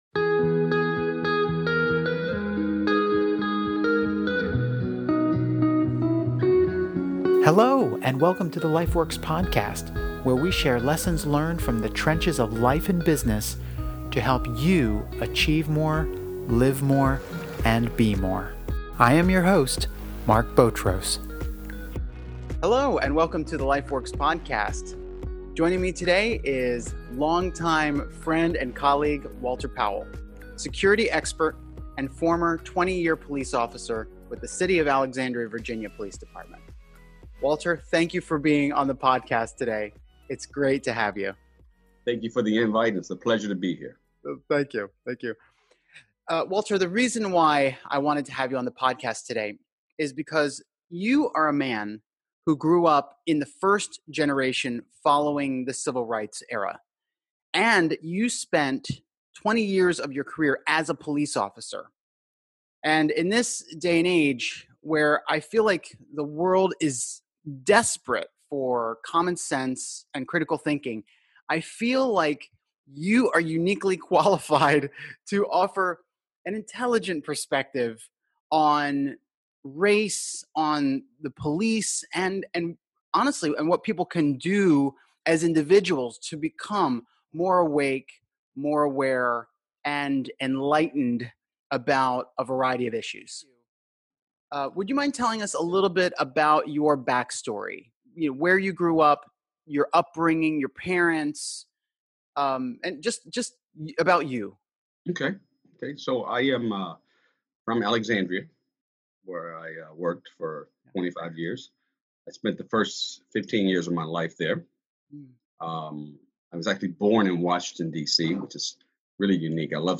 In this interview we cover some of the following: A forensic police view of the George Floyd and Rayshard Brooks cases What drove him to become a police officer Black culture How to avoid resentment in the face of racism What he has taught his children about this society An insider look of what it’s like to be a black police officer Why black communities in particular are targeted Combatting Bias The power of being NICE and Respectful Predictions on the Future of Police Departments Defunding Police Can we end Racism?